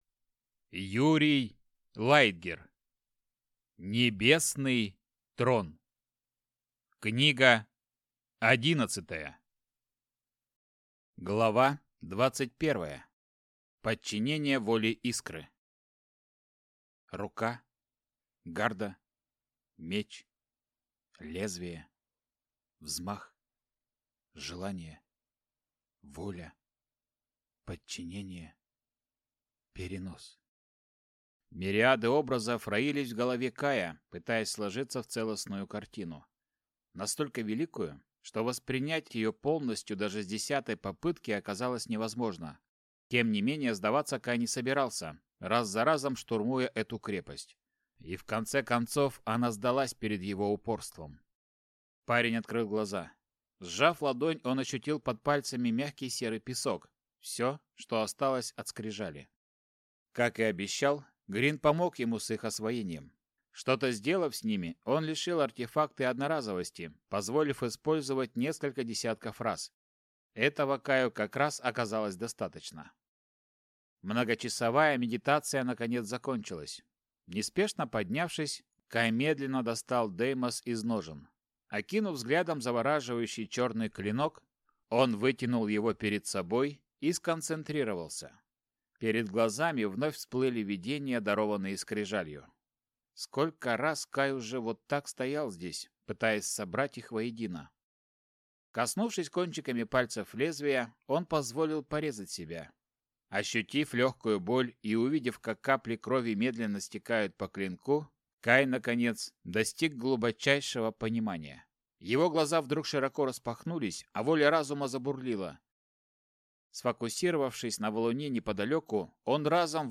Аудиокнига Небесный Трон. Книга 11. Часть 2 | Библиотека аудиокниг